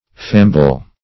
Famble \Fam"ble\